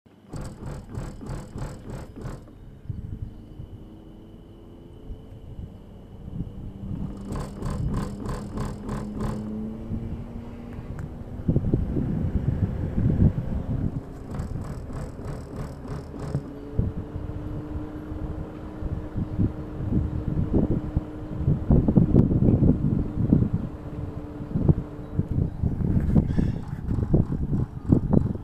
Au niveau de l'UE l'hélice du ventilo se met à tourner et un peu après j'entends 3 sortes de clac assez audibles comme si le compresseur essayait de démarrer sans y parvenir et si on insiste le système se met en défaut mais ça se réarme par la suite.
J'ai fait un enregistrement comme demandé, j'ai dû le convertir de AAC en MP3, pour moi c'est bien audible j'espère qu'il en sera de même chez vous.
C'est ce bruit de RER qui quitte une gare en prenant de la vitesse qu'on entend en arrière plan (sonore).